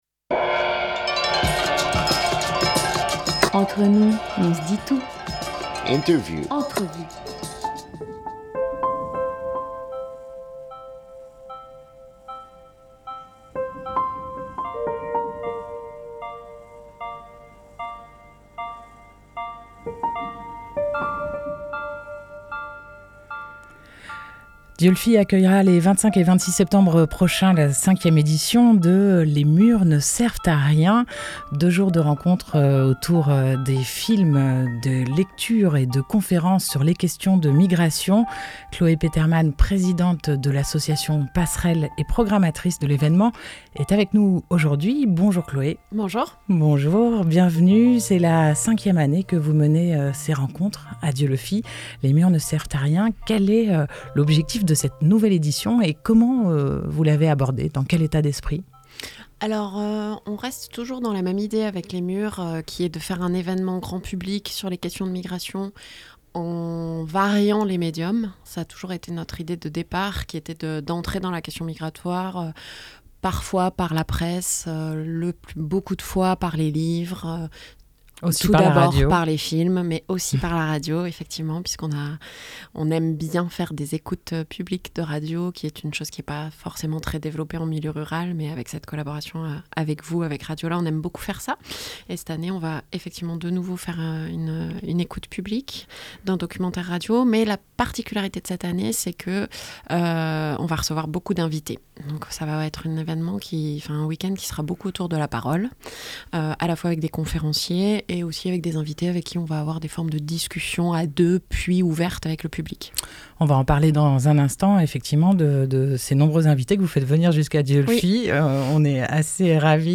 7 septembre 2021 14:50 | Interview